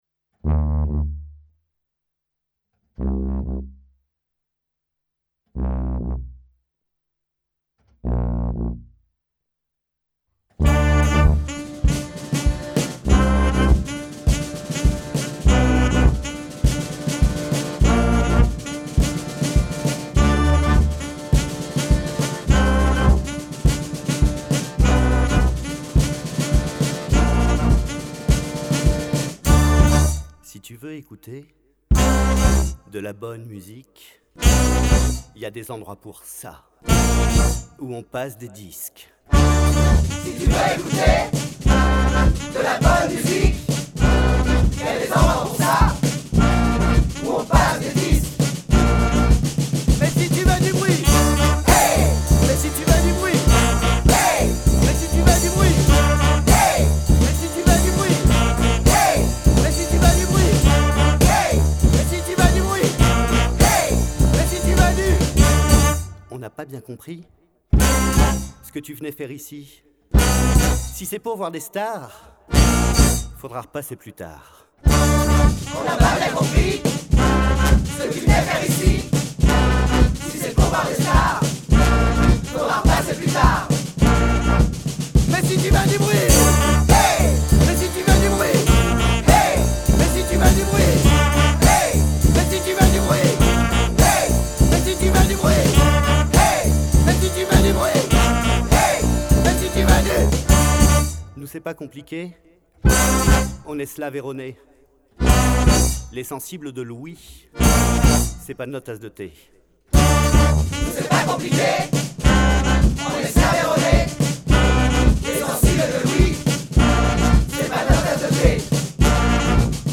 Enregistré et mixé au studio Millau’Zic-Priam